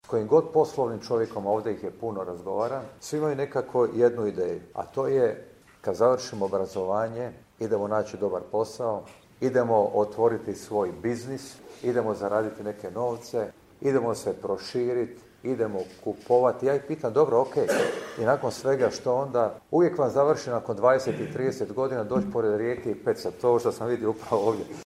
Započeo ju je u Garešnici, gdje se sastao s članovima Gradskog odbora stranke i građanima u Centru za posjetitelje.